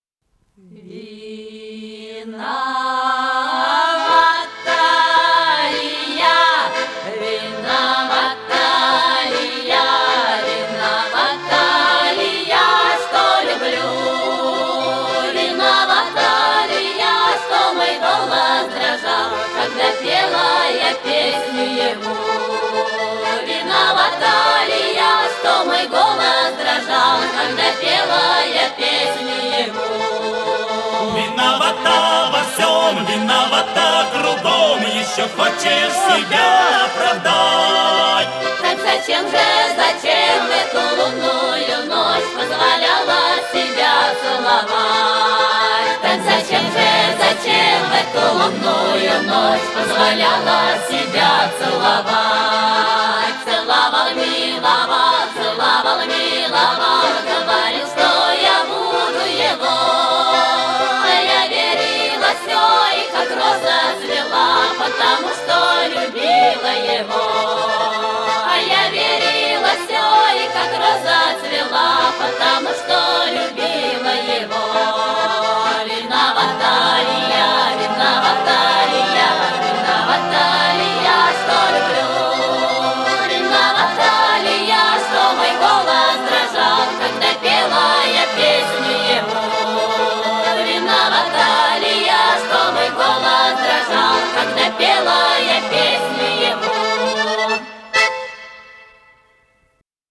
ИсполнителиНадежда Бабкина - пение
АккомпаниментАнсамбль "Русская песня"